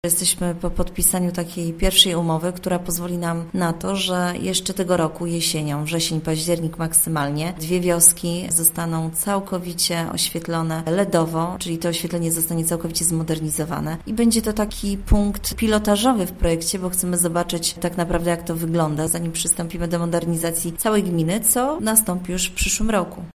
– Będzie estetyczniej, bo pojawią się nowe oprawy, zamiast starych, no i będzie oszczędniej, bo lampy są mniej energochłonne – informuje Katarzyna Kromp, wójt gminy Tuplice.